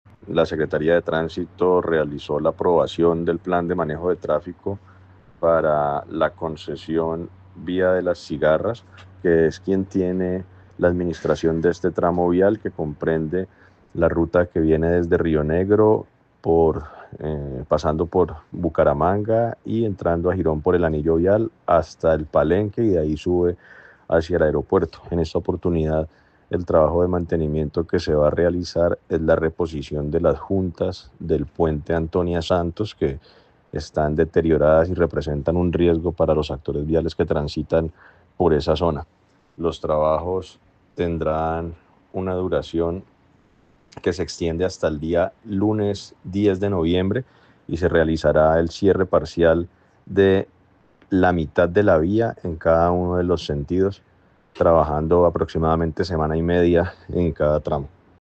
Juan José Gómez, secretario de tránsito de Girón